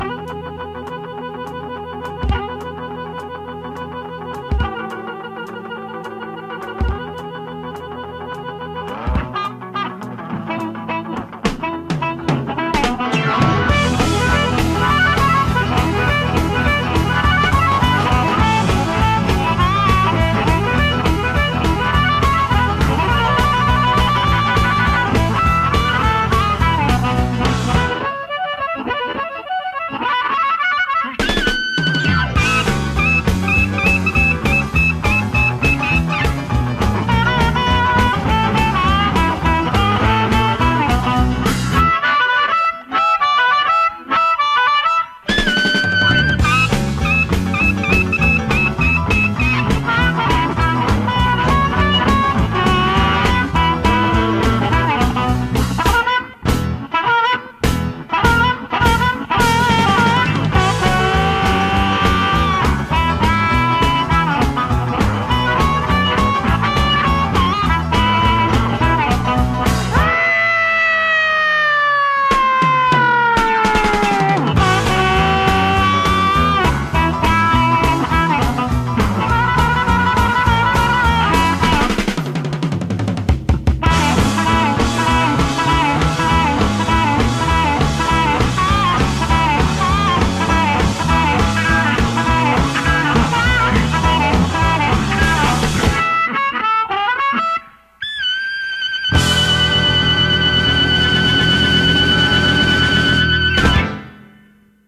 BPM200-214
Audio QualityMusic Cut